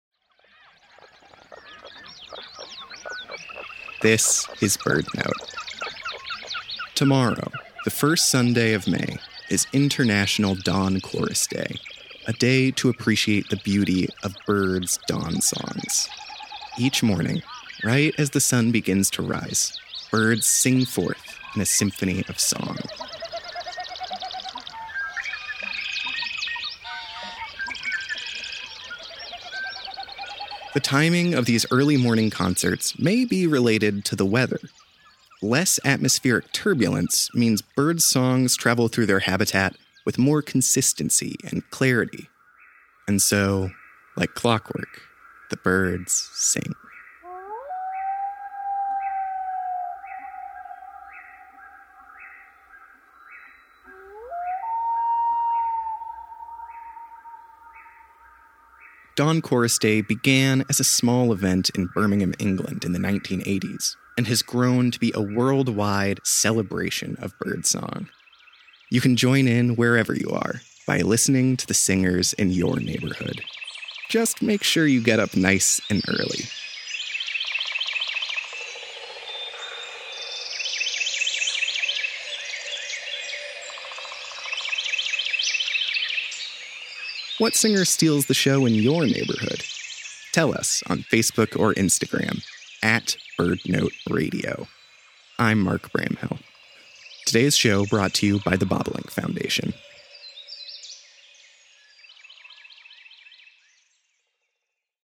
Dawn Chorus Day began as a small event in Birmingham, England in the 1980s and has grown to be a worldwide celebration of birdsong. You can join in wherever you are by listening to the singers in your neighborhood – like this Eurasian Wren.